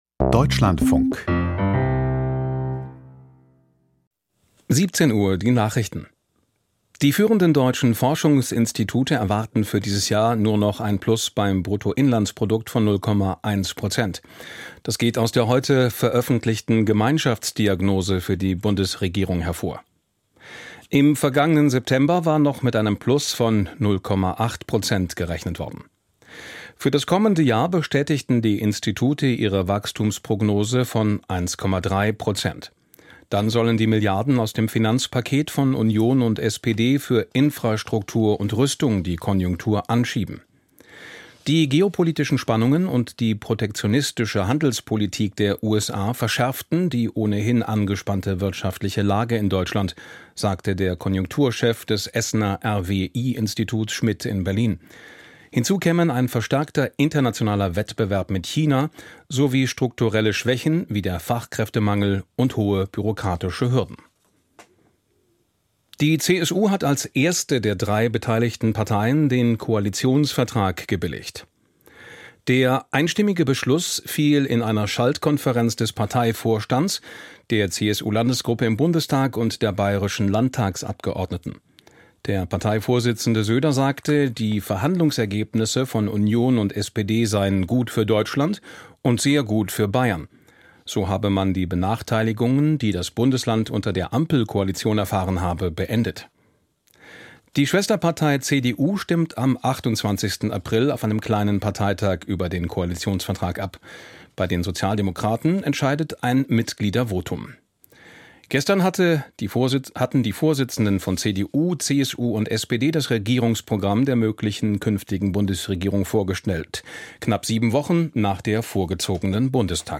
Die Deutschlandfunk-Nachrichten vom 10.04.2025, 17:00 Uhr